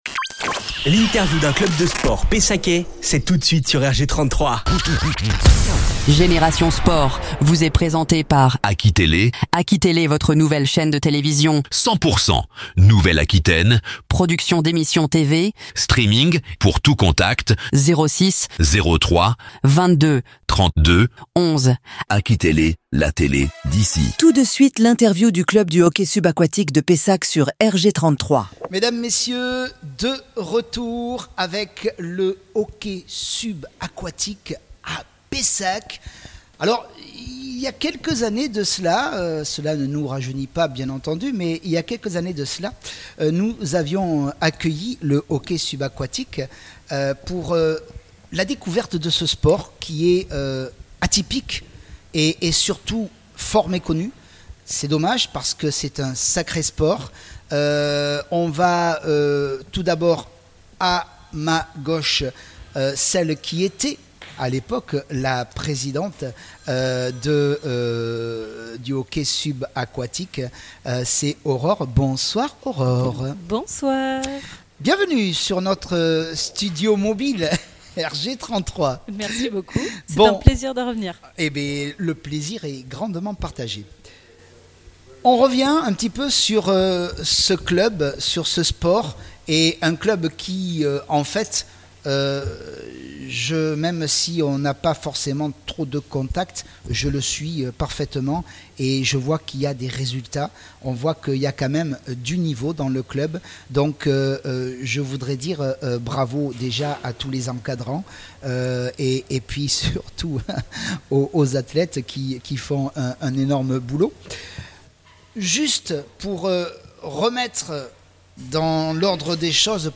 Interview par Radio Génération 33 - Hockey Sub Pessac
Radio Génération 33 a installé son studio mobile au Stade Nautique de Pessac pour faire découvrir le hockey subaquatique et le club de Pessac.